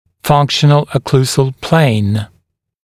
[‘fʌŋkʃ(ə)n(ə)l ə’kluːzəl pleɪn][‘фанкш(э)н(э)л э’клу:зэл плэйн]функциональная окклюзионная плоскость